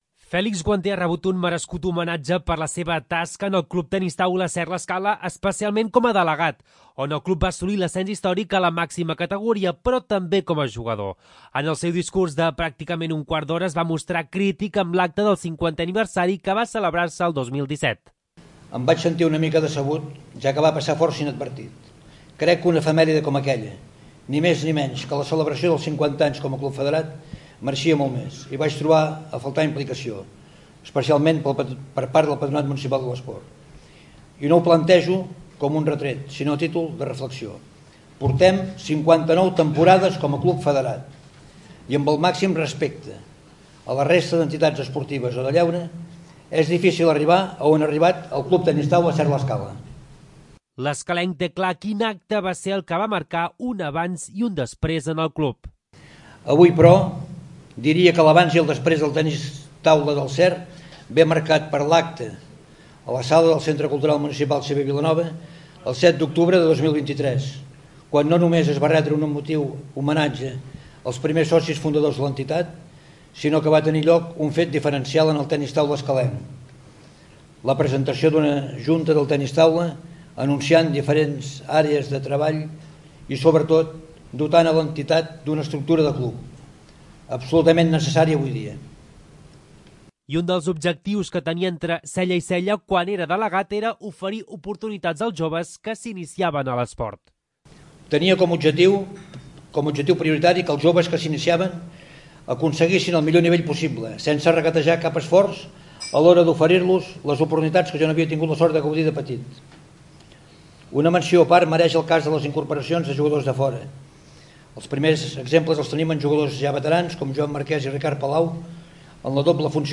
També van parlar amics i companys.